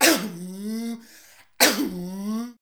COUGH.wav